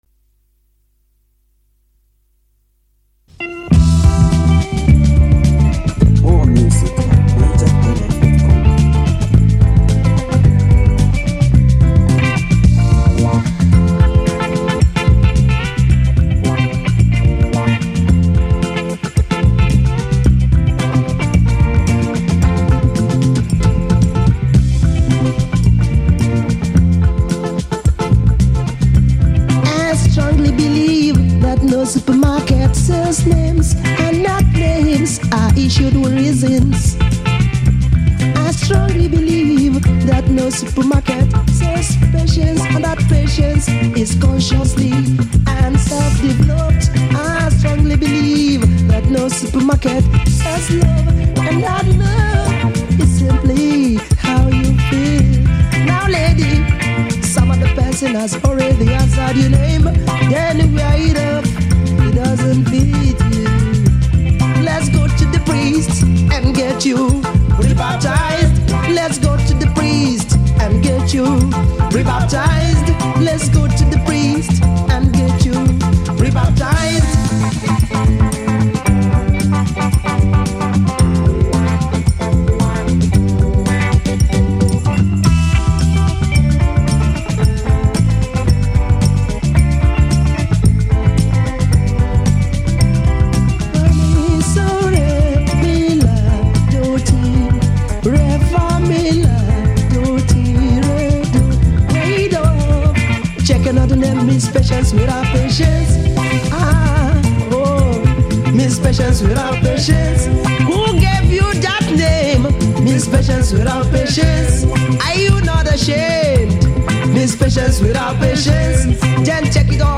Home » Ragae
Nigerian Reggae Music